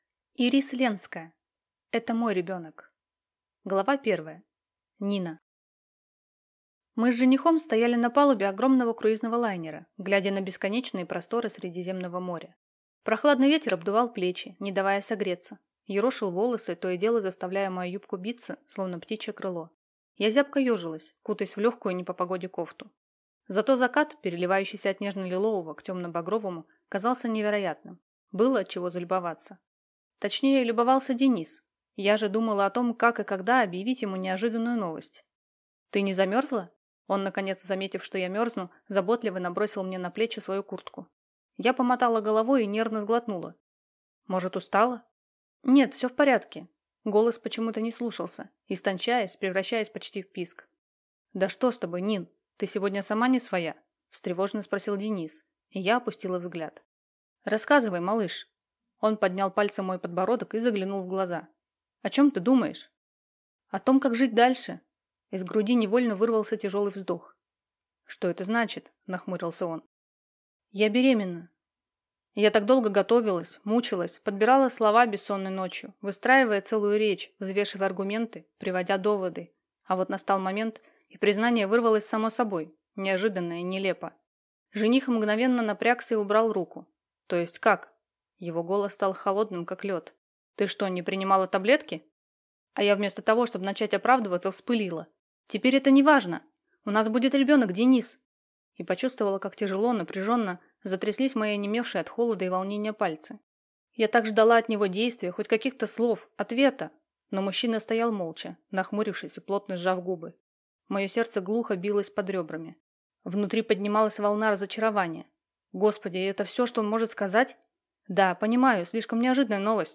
Аудиокнига Это мой ребёнок | Библиотека аудиокниг